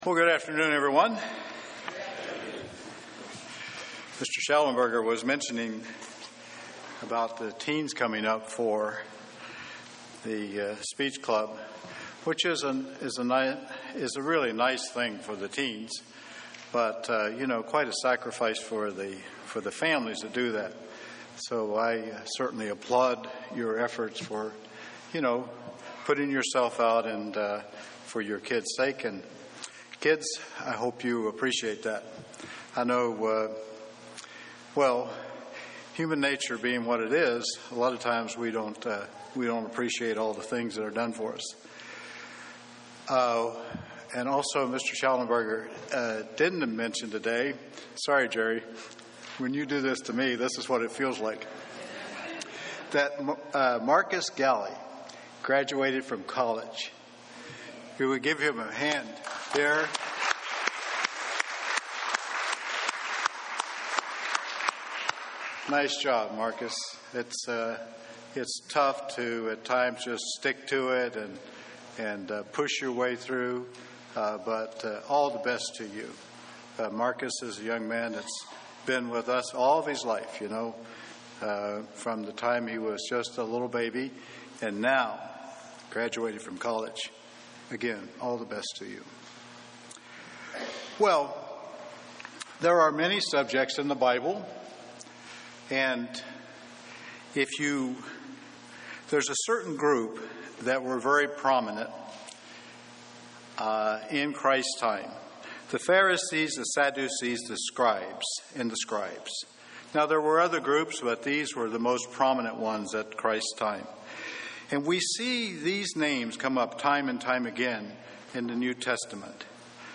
Given in Columbus, OH
UCG Sermon Studying the bible?